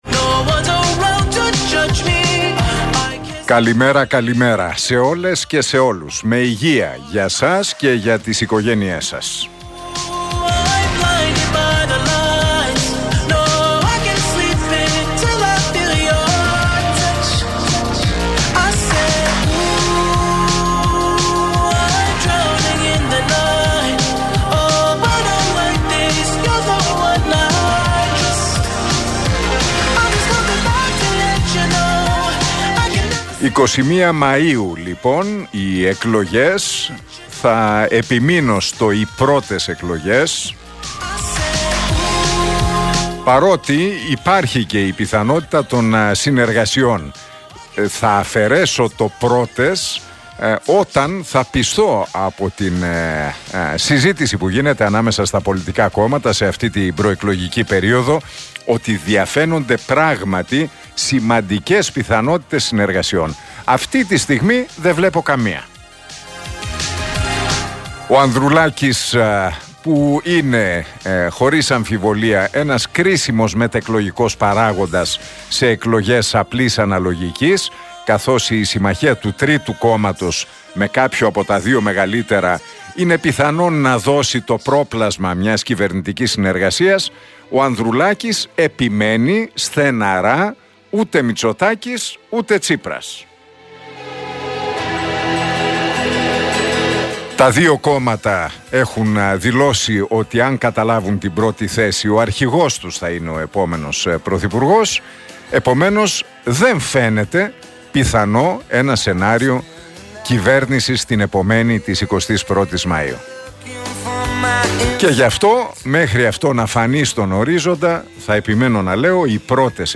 Ακούστε το σχόλιο του Νίκου Χατζηνικολάου στον RealFm 97,8, την Τετάρτη 29 Μαρτίου 2023.